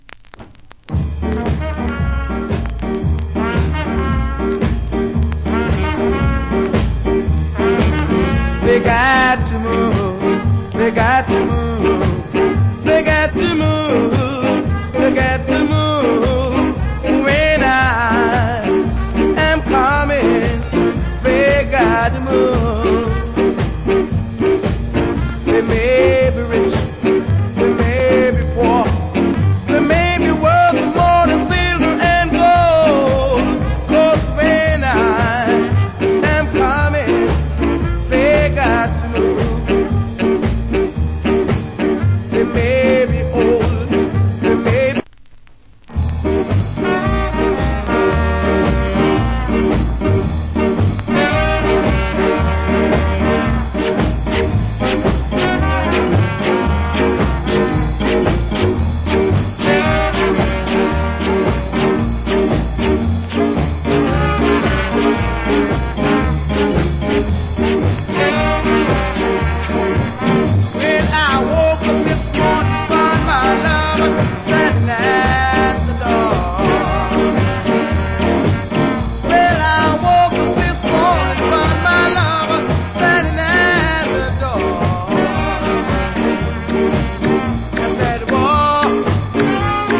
Ska Vocal.